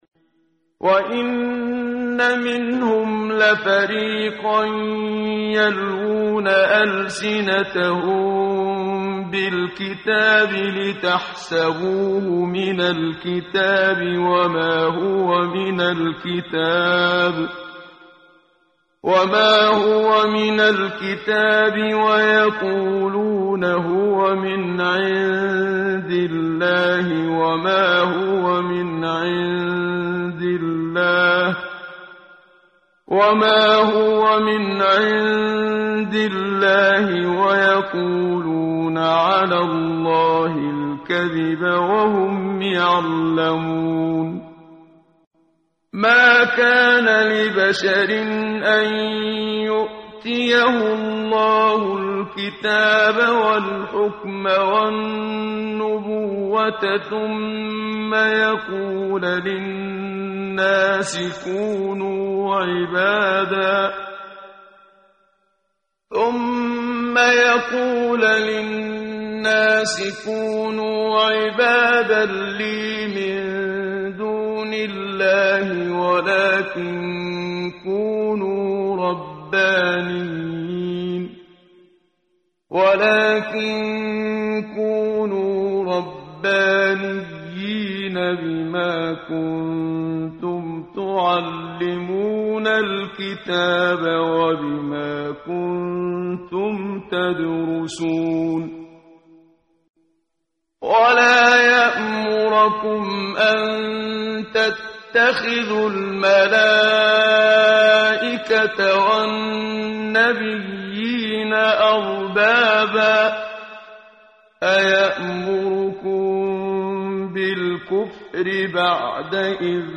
ترتیل صفحه 60 سوره مبارکه آل عمران (جزء سوم) از سری مجموعه صفحه ای از نور با صدای استاد محمد صدیق منشاوی
quran-menshavi-p060.mp3